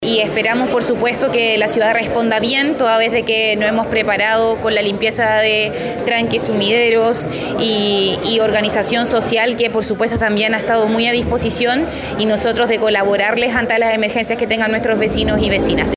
Para este sistema frontal se llevaron a cabo diferentes Comités para la Gestión del Riesgo de Desastres en las comunas de la región, entre ellas Valparaíso, donde la alcaldesa, Camila Nieto, detalló de qué forma se preparó la ciudad.
cu-sistema-frontal-camila-nieto.mp3